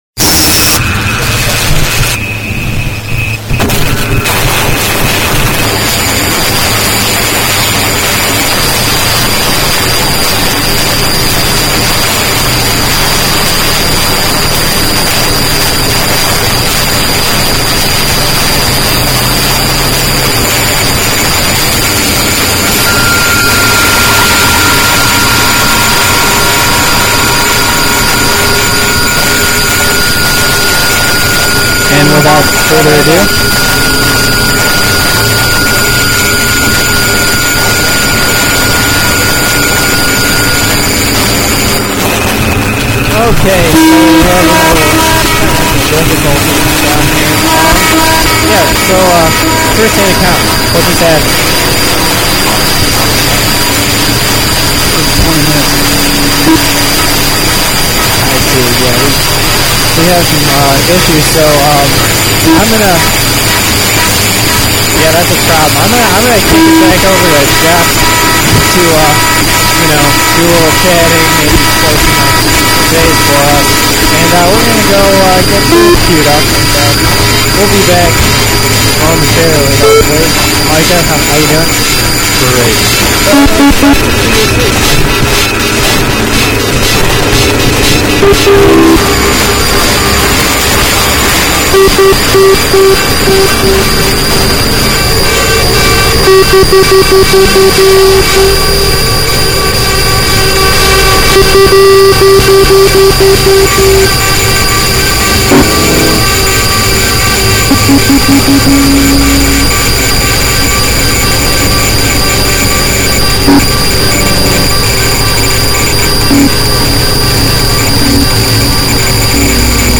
added in some trademarked harshness